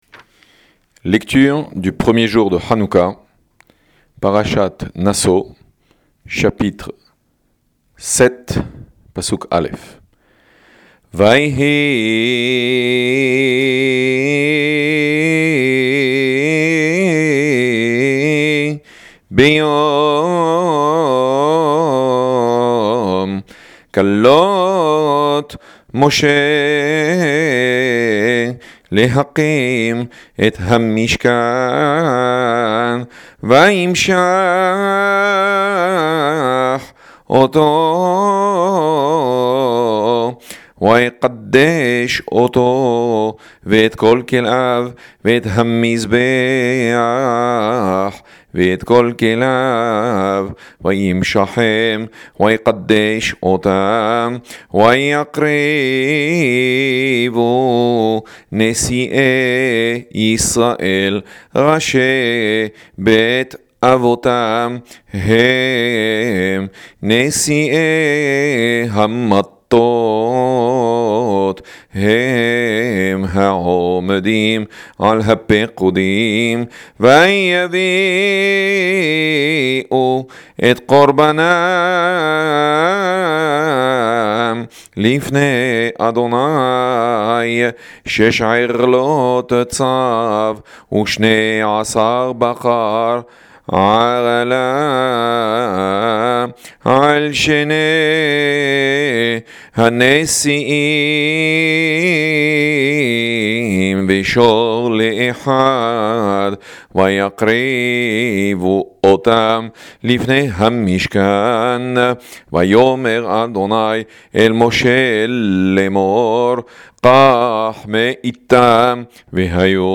10. Hanoukah - Lecture du 1er Jour de H’anouka